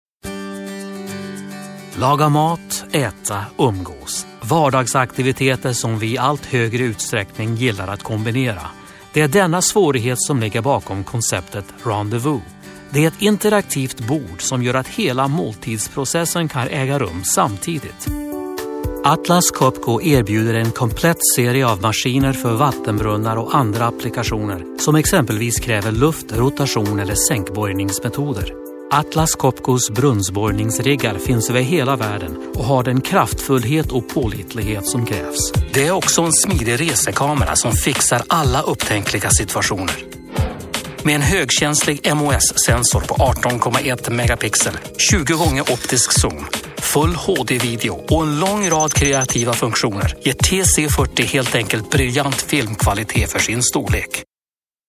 Upscale, corporate, elegant, ironic
Sprechprobe: Werbung (Muttersprache):